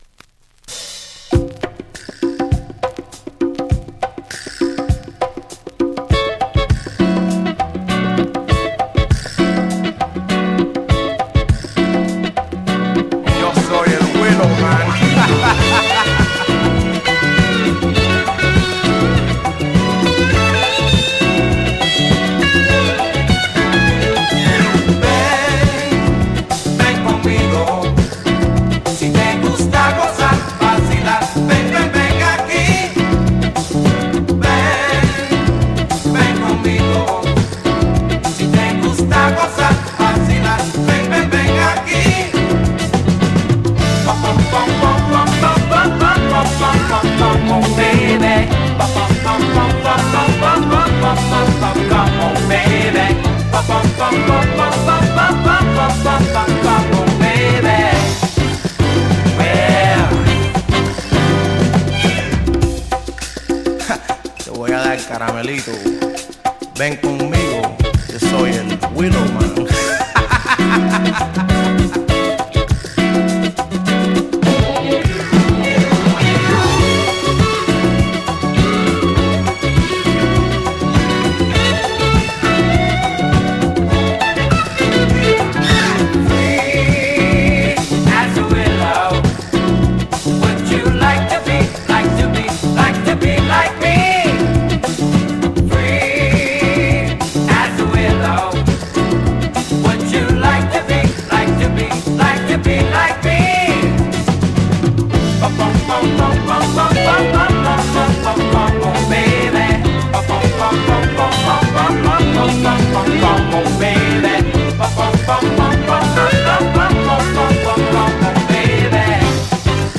Free Soul, Disco us